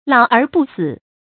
老而不死 注音： ㄌㄠˇ ㄦˊ ㄅㄨˋ ㄙㄧˇ 讀音讀法： 意思解釋： 本是孫子罵舊友原壤年老無德可稱術的話，后亦指年老而不利于社會國家或拖累后輩。